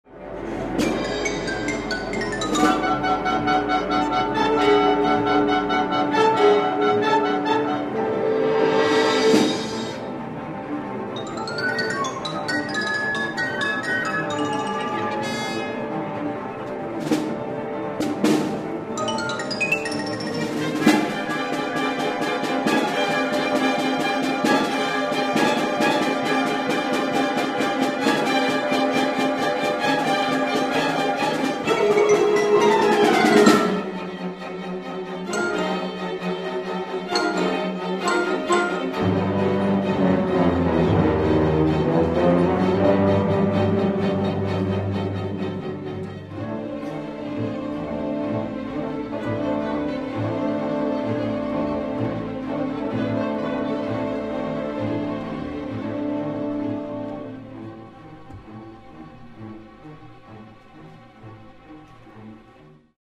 Enregistrement live.